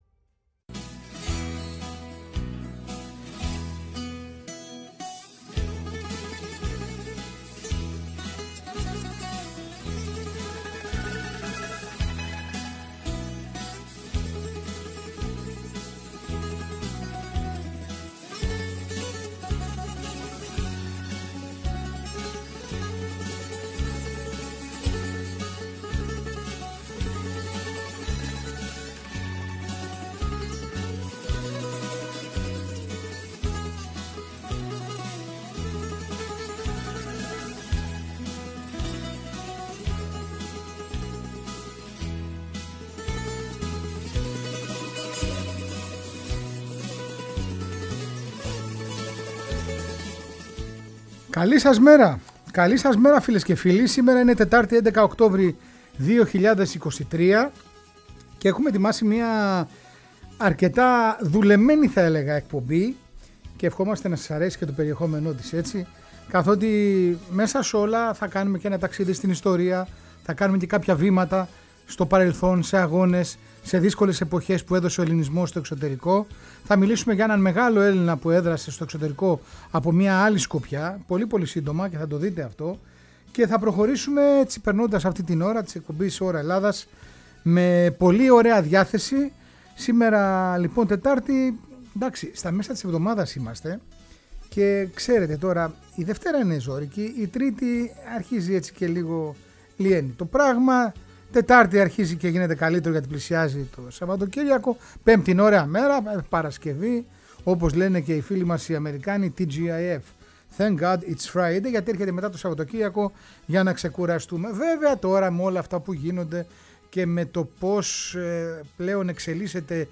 Διπλή αφιερωματική εκπομπή. Η συγκλονιστική ιστορία του Έλληνα λοχαγού των Βιετμίνχ Κώστα Σαραντίδη και η ιστορία της μετανάστευσης των Ελλήνων στο Σικάγο.
Μια ντοκιμαντερίστικη ραδιοφωνική εκπομπή με τη σφραγίδα διαφορετικών… Ελλήνων που έγραψαν τη δική του ιστορία μακριά από την γενέτειρα.